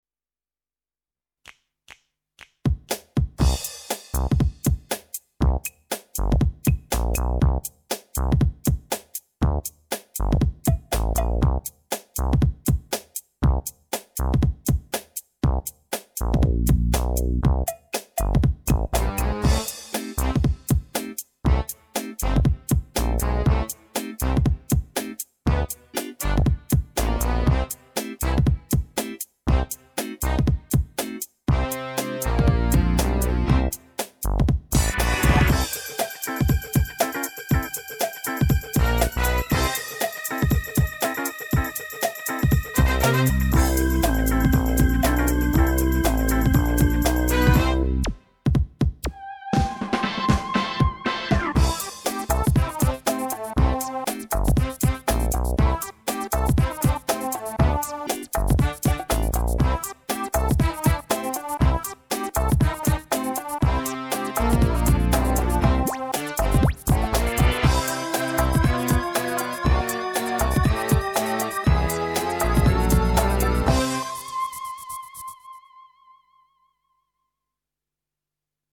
minus Flute